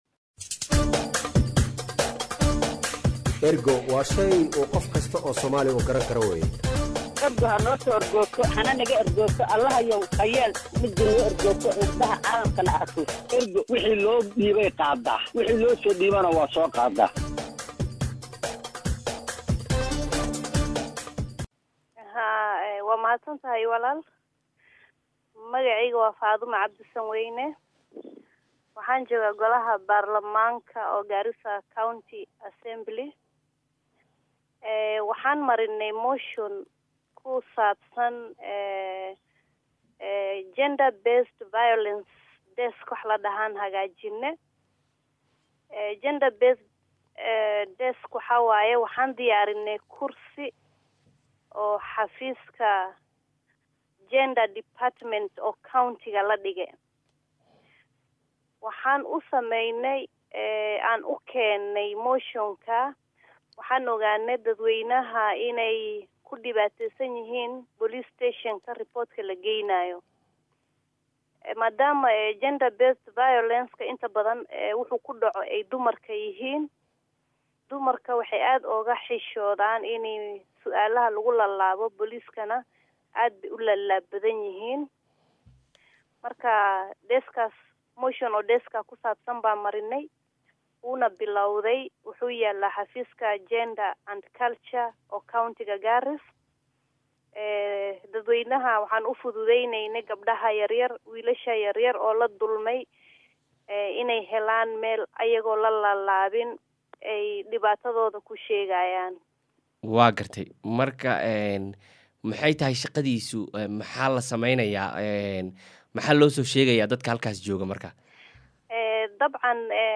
Wareysi: Garissa oo lala dagaallamaya xadgudubyada ka dhanka ah dumarka